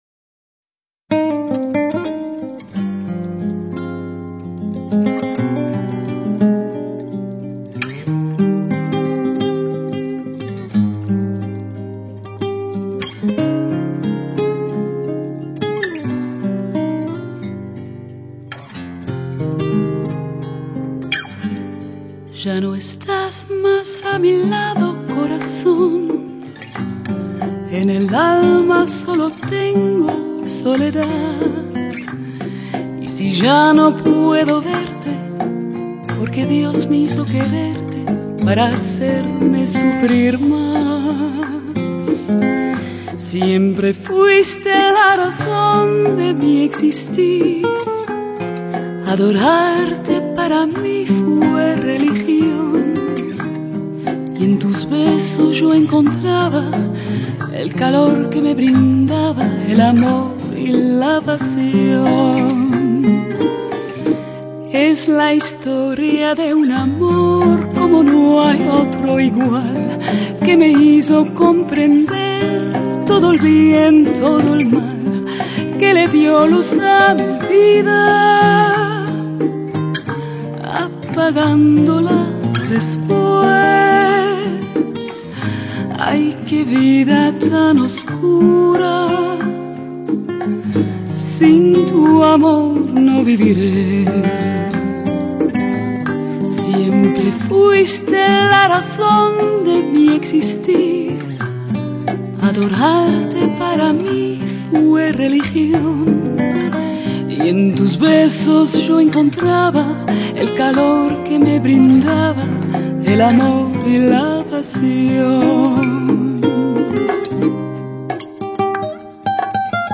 平易近人的旋律搭配上他温暖轻松的唱腔
不过，掩不住她歌声中流露出的浓情蜜意